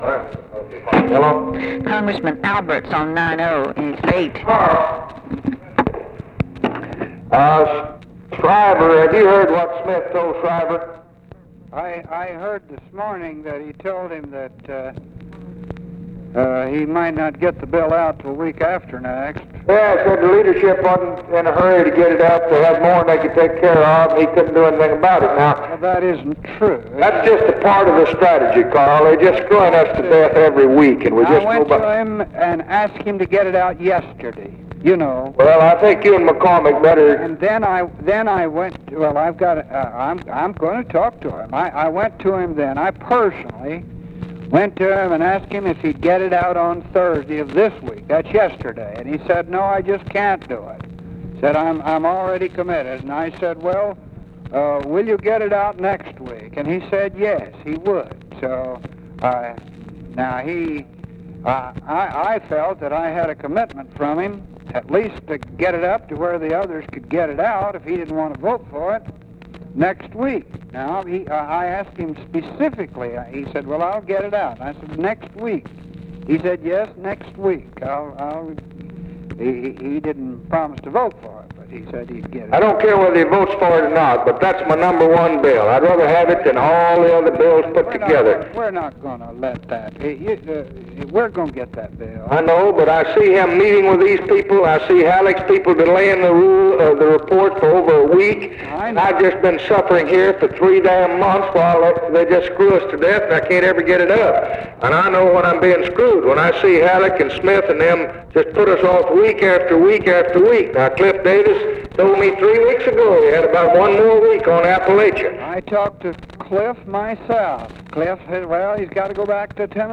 Conversation with CARL ALBERT, June 5, 1964
Secret White House Tapes